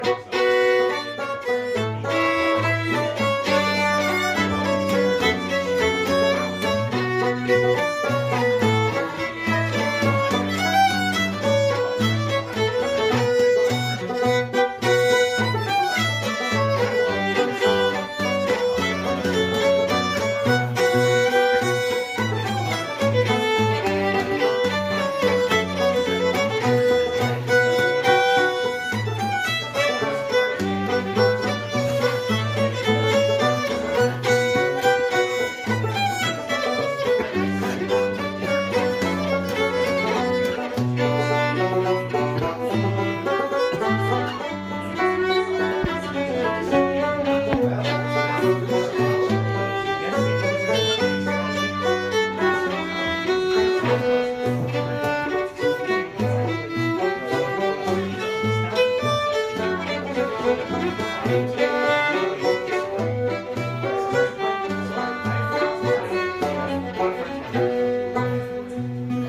One of the tunes that was played at the music session I went to last night is called If you do not love me, go climb a tree (it may have other names) and goes something like this: